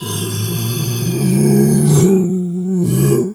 bear_roar_04.wav